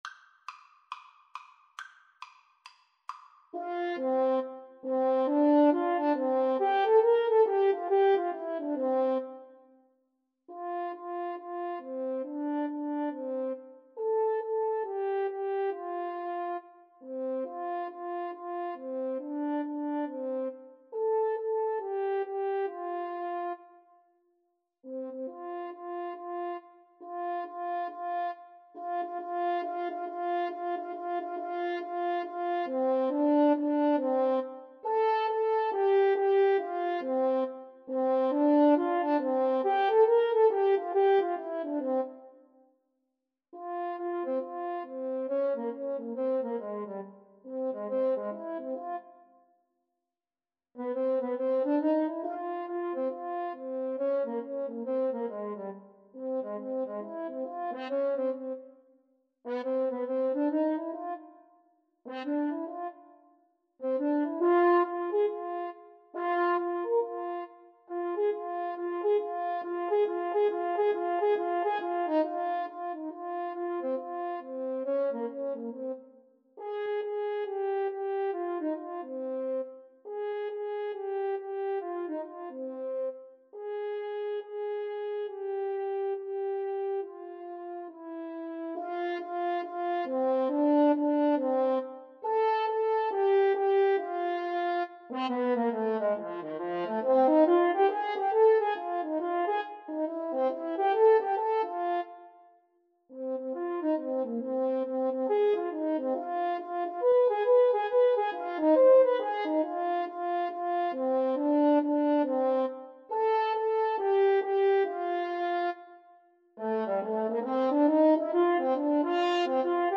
A jazzy take on this classic children’s tune!
Molto Allegro, Swung =c.69 (View more music marked Allegro)
jazz (View more jazz French Horn Duet Music)